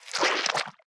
File:Sfx creature spikeytrap attack enter 01.ogg - Subnautica Wiki